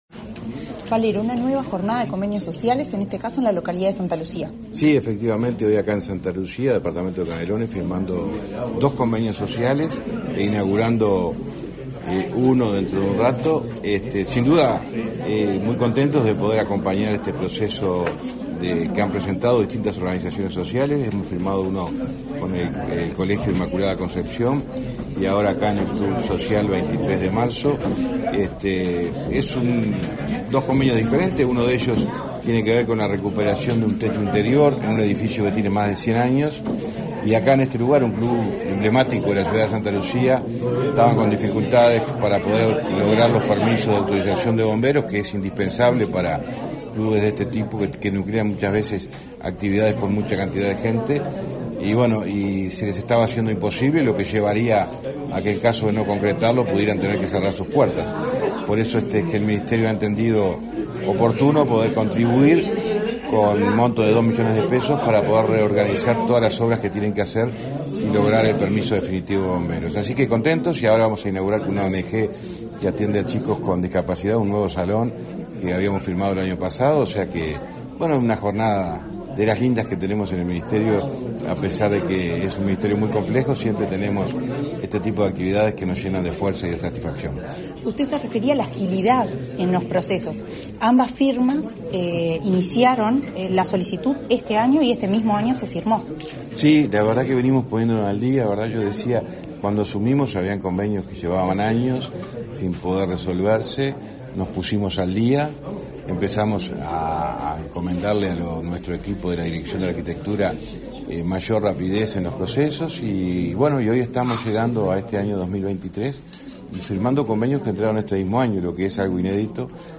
Palabras del ministro del MTOP, José Luis Falero
Palabras del ministro del MTOP, José Luis Falero 18/12/2023 Compartir Facebook X Copiar enlace WhatsApp LinkedIn Este 18 de diciembre, el Ministerio de Transporte y Obras Públicas (MTOP) firmó convenios sociales en Santa Lucía, Canelones. Para apoyar al Colegio Inmaculada Concepción asumió uno de los compromisos. El ministro José Luis Falero realizó declaraciones.